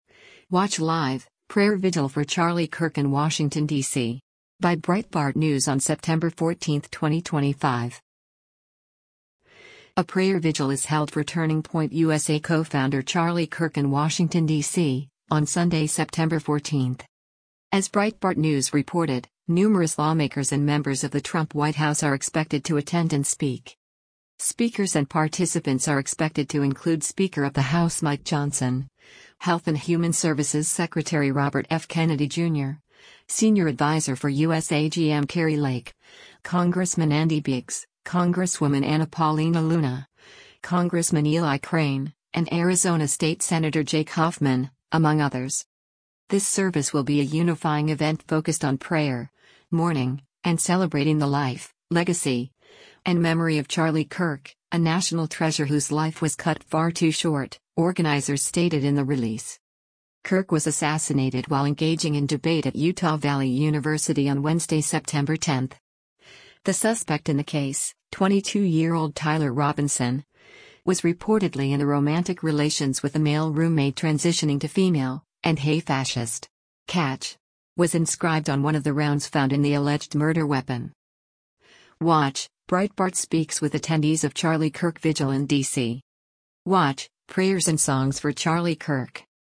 A prayer vigil is held for Turning Point USA co-founder Charlie Kirk in Washington, DC, on Sunday, September 14.